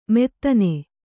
♪ mettane